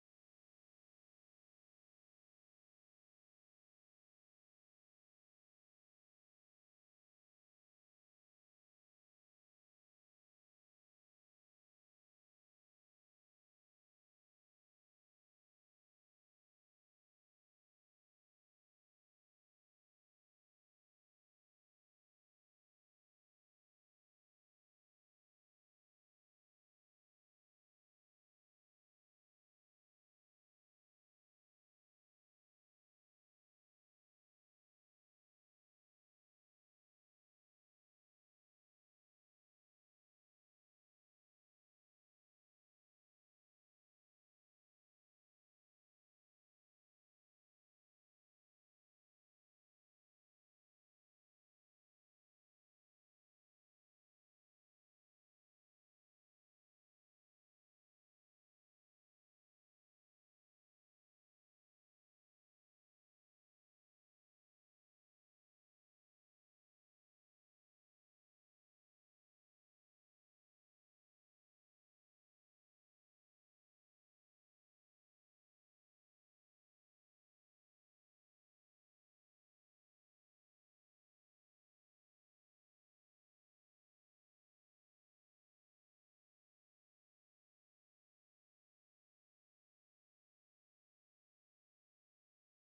サウンドボード収録！！
※試聴用に実際より音質を落としています。